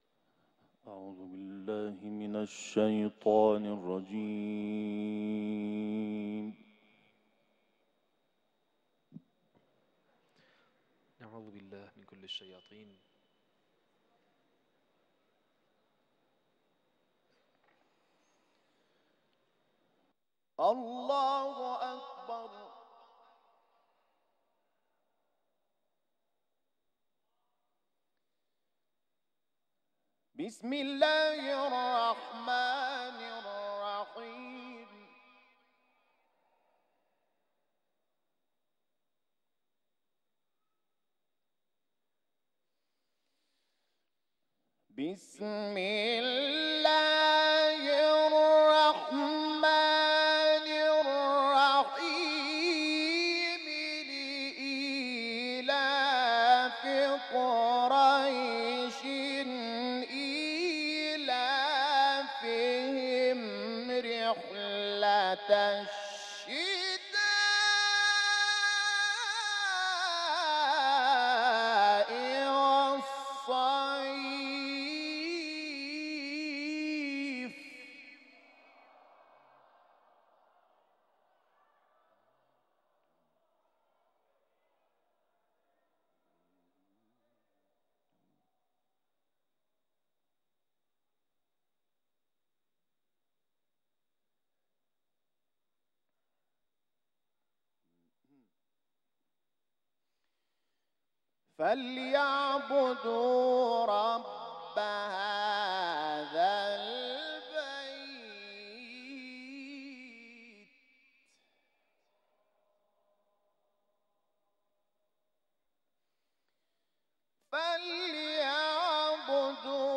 برچسب ها قرآن سوره قریش سوره کوثر محمود شحات انور تلاوت کوتاه مجلسی سالن اجلاس